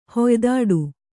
♪ hoydāḍu